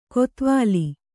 ♪ kotvāli